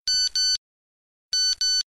Categoria Messaggio